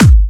VEC3 Bassdrums Trance 68.wav